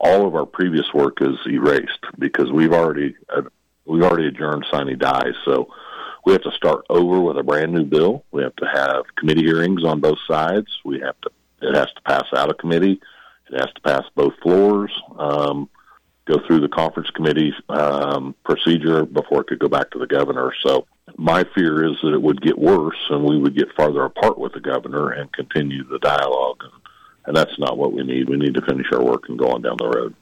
Regarding tax relief, Longbine’s appearance on Wednesday’s morning show comes as lawmakers are still awaiting word on whether or not Kansas Governor Laura Kelly plans to veto a tax relief package which would force lawmakers to return to the capital for a special session. Longbine says it is his hope this will not be necessary as it would mean the legislature would have to start from scratch on an entirely new package.